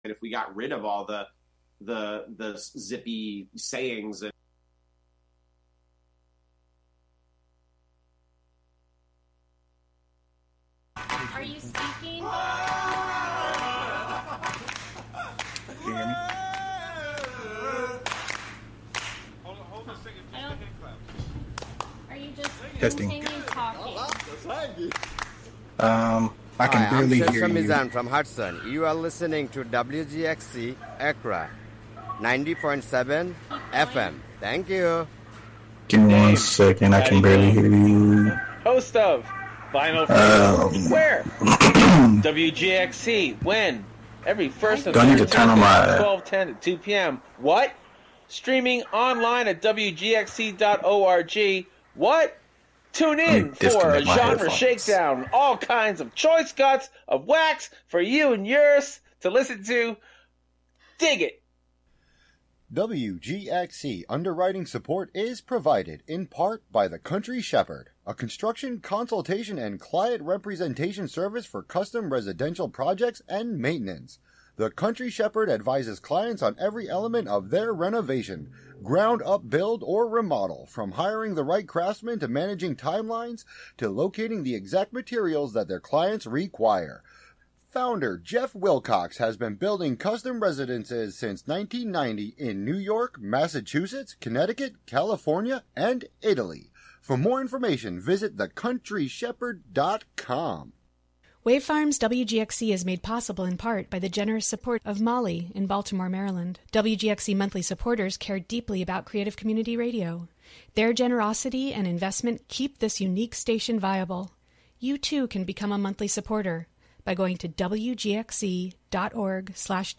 The roundtable discussion at the end of the show, where call-ins from listeners will be taken may help to answer this question!